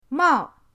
mao4.mp3